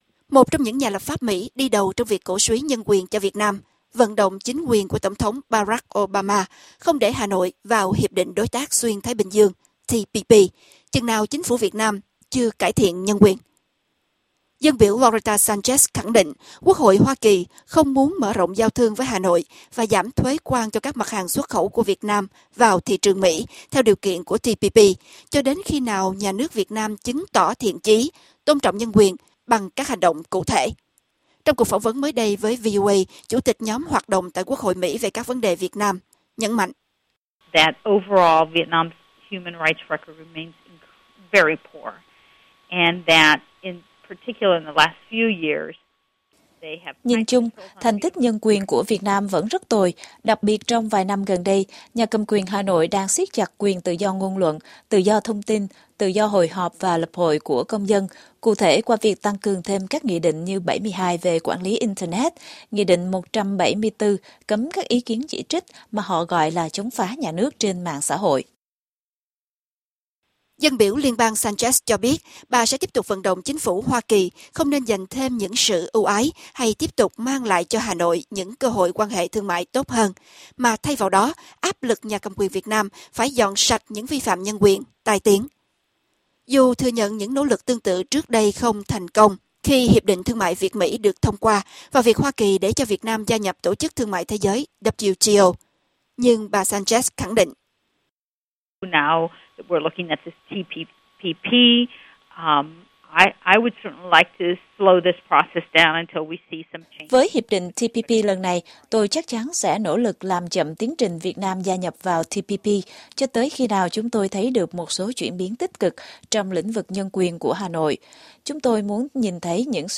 Trong cuộc phỏng vấn mới đây với đài VOA, Chủ tịch Nhóm hoạt động tại Quốc hội Mỹ về các vấn đề Việt Nam, nhấn mạnh: